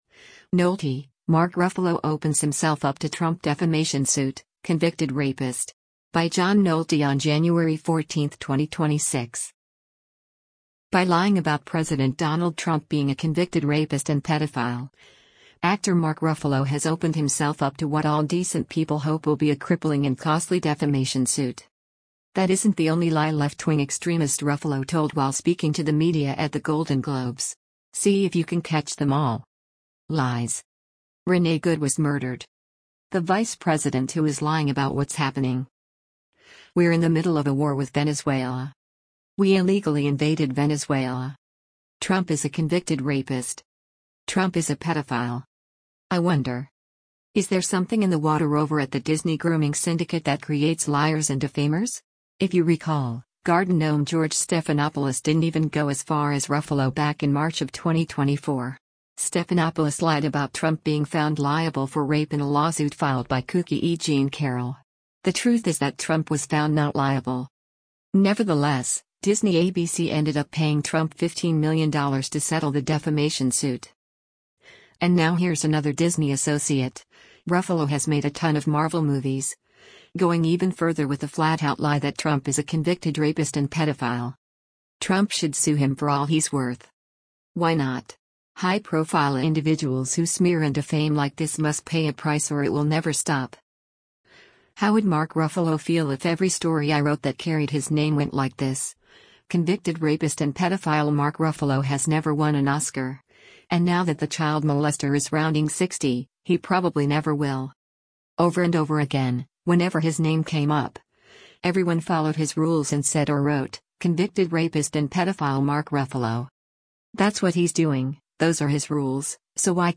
That isn’t the only lie left-wing extremist Ruffalo told while speaking to the media at the Golden Globes.